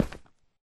Minecraft / step / stone1.ogg
stone1.ogg